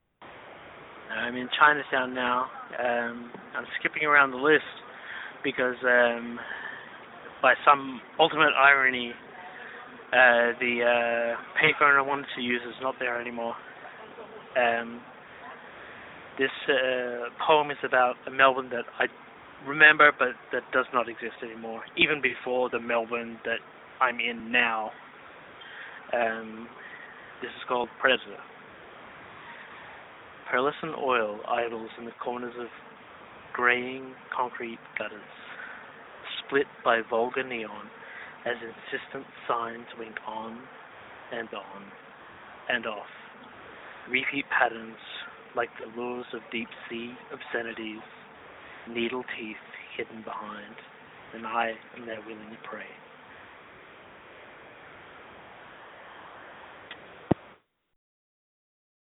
and now  I have; LOWWIRE is  a collection of poems  by me read
into payphones, I hope you enjoy.